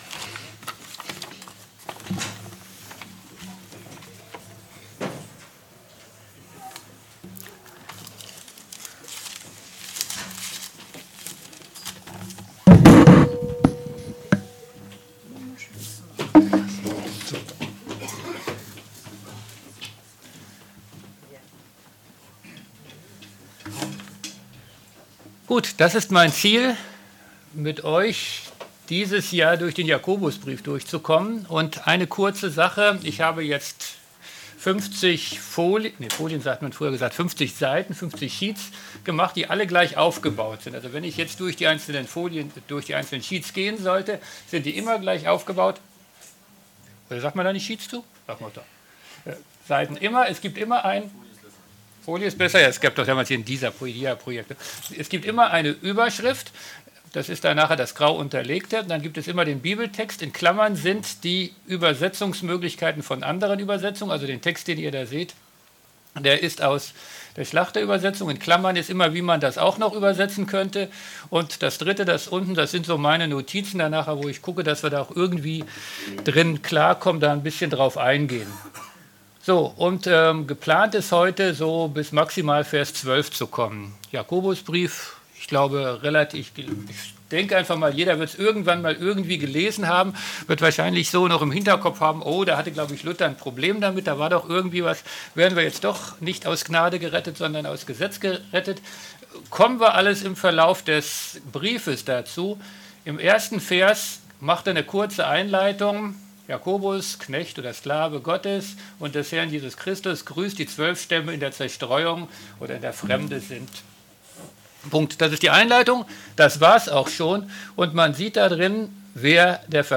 MP3 Predigten